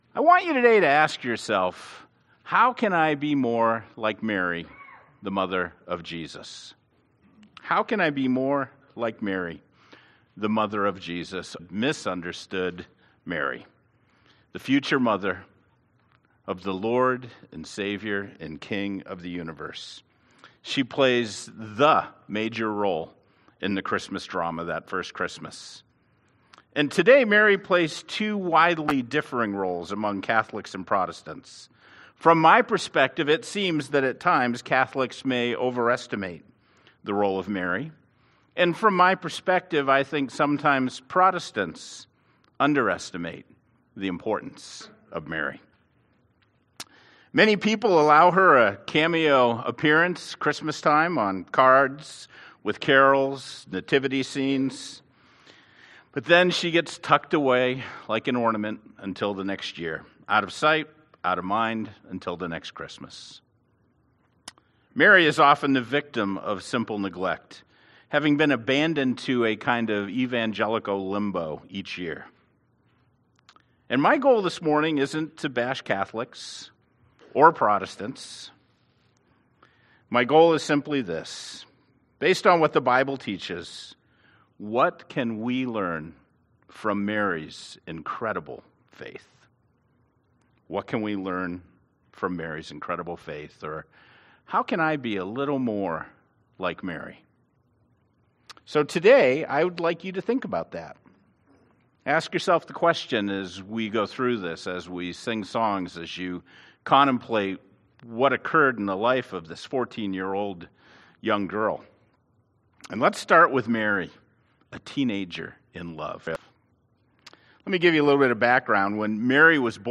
Sermon-12-09-18.mp3